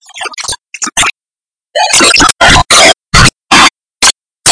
New Beat